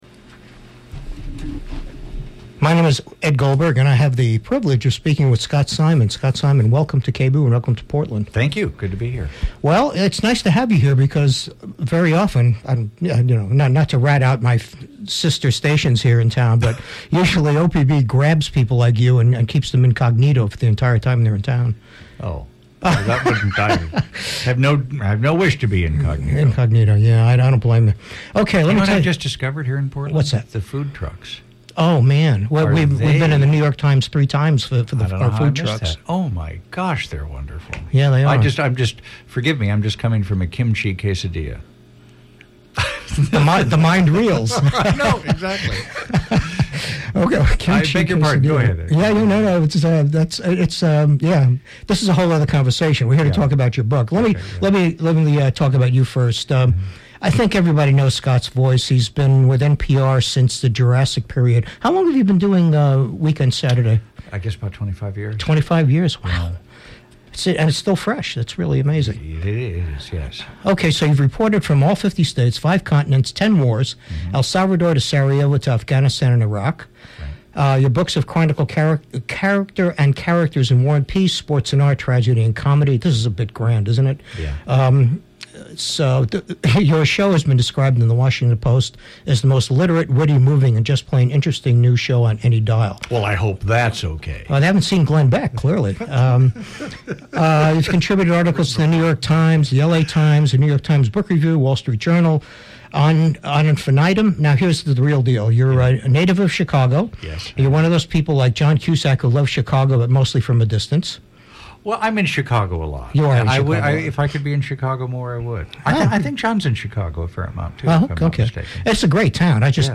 Radiozine